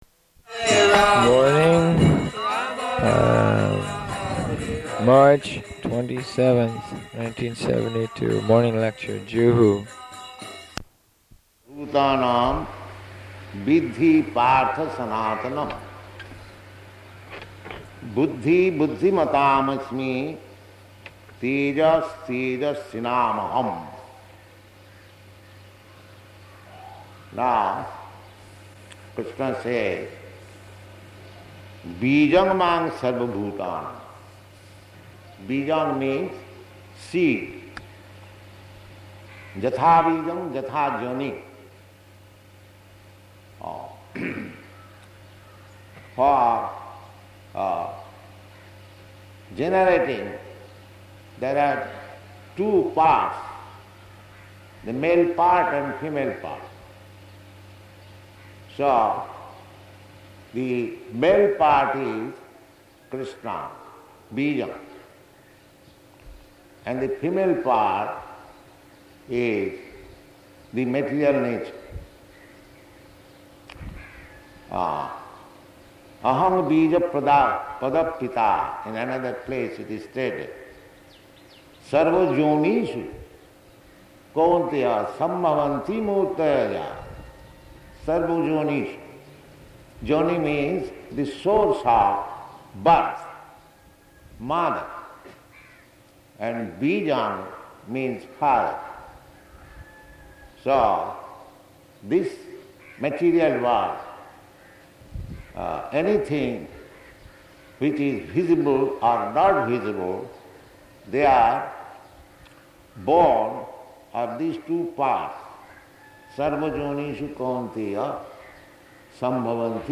March 27th 1972 Location: Bombay Audio file
morning lecture, Juhu.